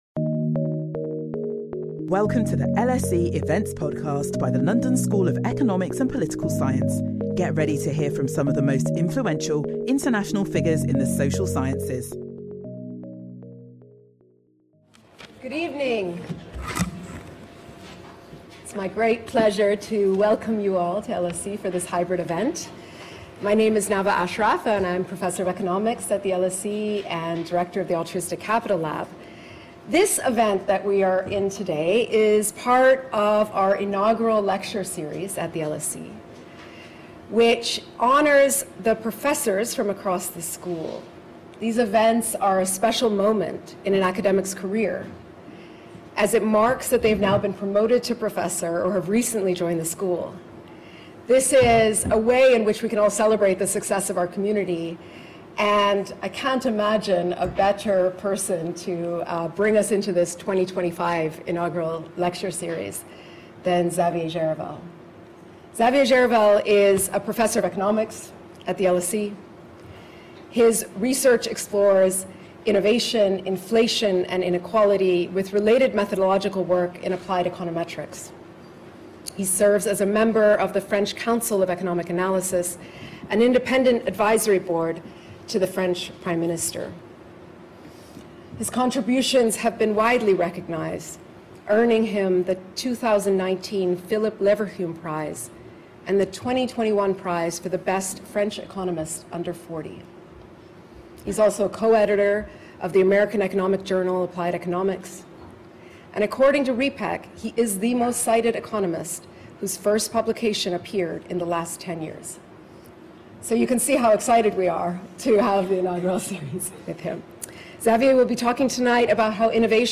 inaugural lecture